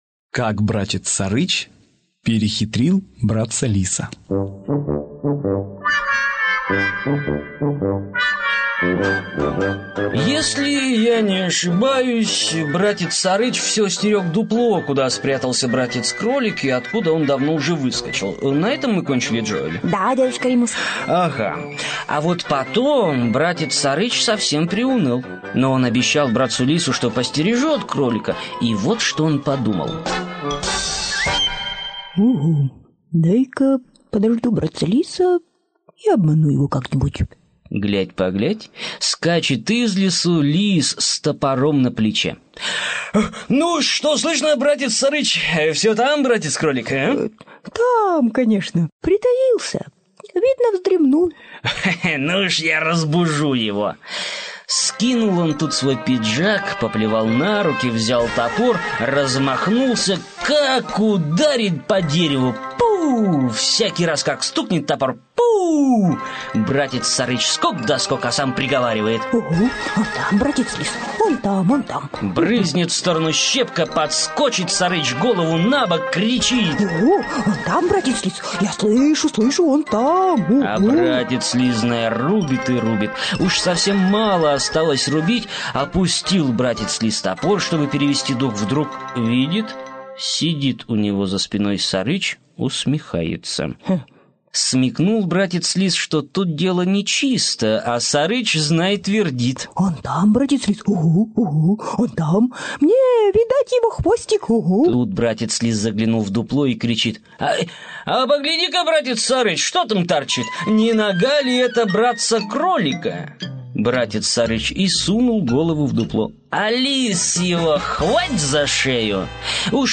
Аудиосказка «Как Братец Сарыч перехитрил Братца Лиса»